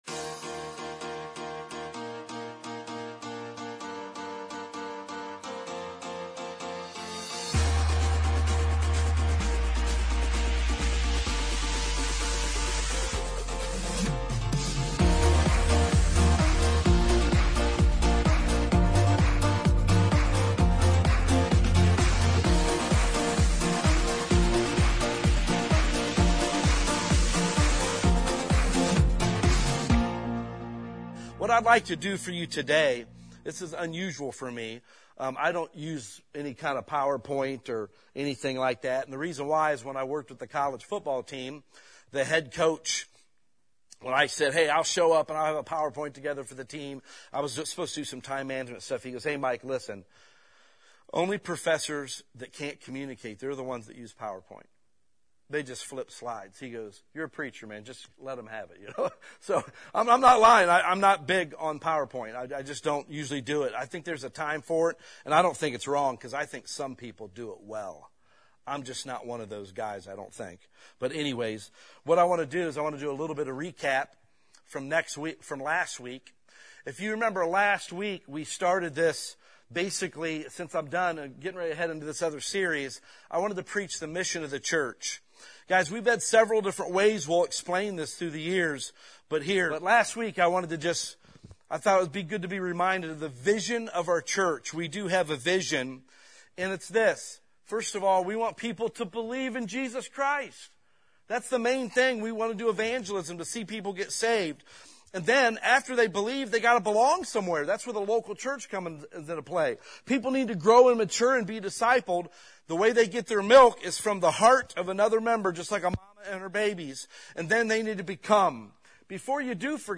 Sermons | Maple City Baptist Church